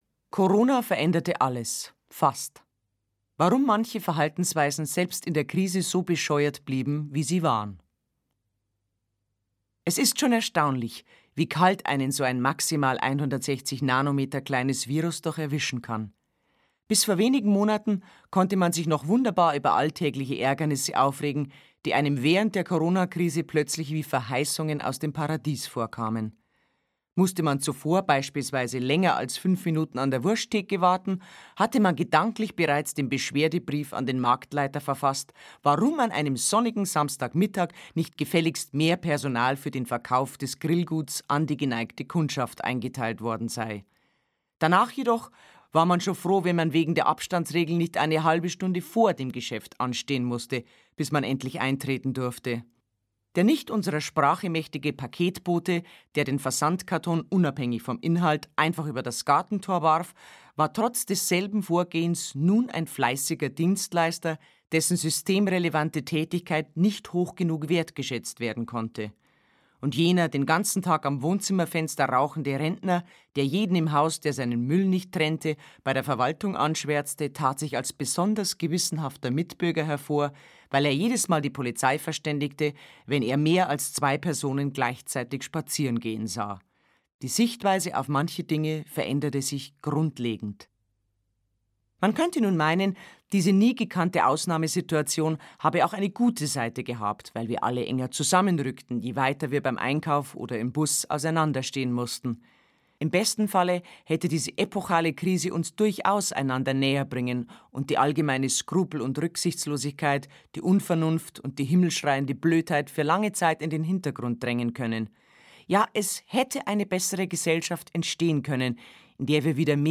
Monika Gruber, Andreas Hock (Sprecher)
Schlagworte Doppelmoral • Gesellschaftskritik • Gesellschaftssatire • Gutmenschen • Hörbuch Autorenlesung • Hörbuch Humor • Hörbuch Sachbuch • Humor • Kabarett • Meinungsfreiheit • Mitläufer • Political Correctness • Satire • Verschwörungstheorien